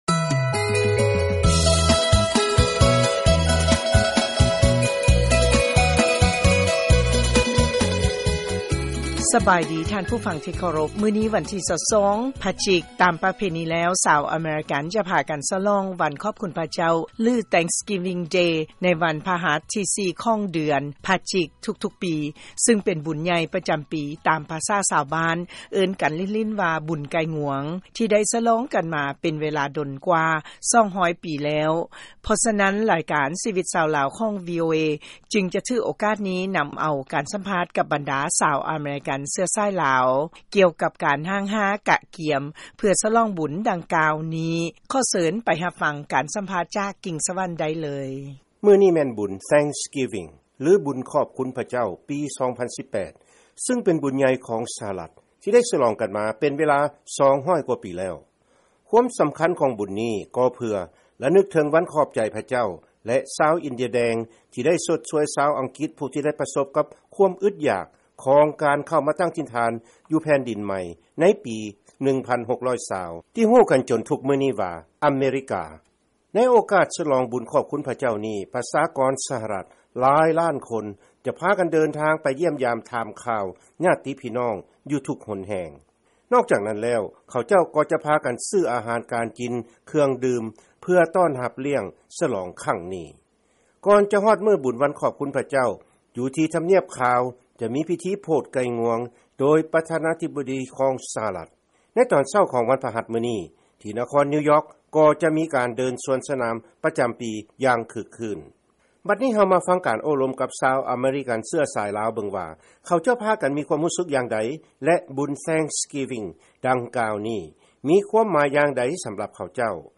ເຊີນຟັງການສຳພາດ ບັນດາສະຕີລາວອາເມຣິກັນ ກ່ຽວກັບການສະຫຼອງບຸນ ວັນຂອບຄຸນພະເຈົ້າ